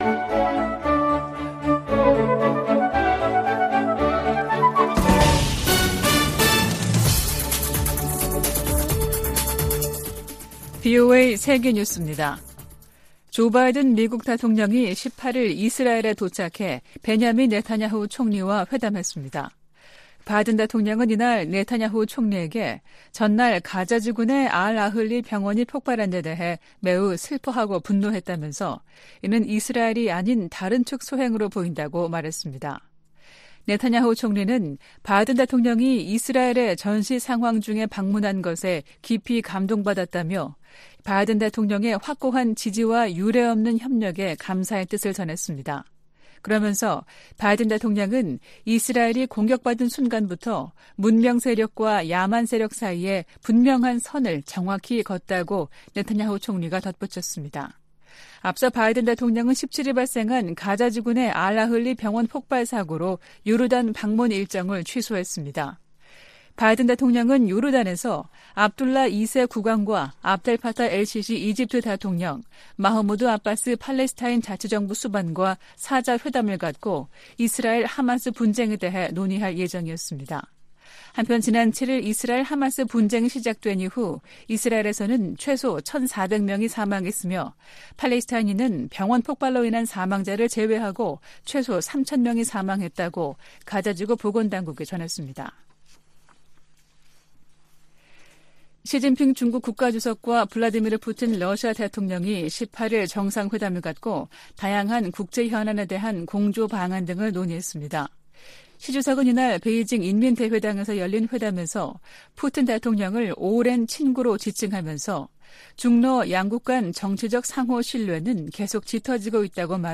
VOA 한국어 아침 뉴스 프로그램 '워싱턴 뉴스 광장' 2023년 10월 19일 방송입니다. 미 국무부가 러시아 외무장관 방북과 관련해 러시아가 첨단 군사기술을 북한에 넘길 것을 우려하고 있다고 밝혔습니다. 미 인도태평양사령관은 북한과 러시아 간 무기 거래 등 최근 움직임으로 역내 위험성이 커졌다고 지적했습니다. 팔레스타인 무장 정파 하마스가 가자지구에서 북한제 무기를 사용한다고 주한 이스라엘 대사가 VOA 인터뷰에서 말했습니다.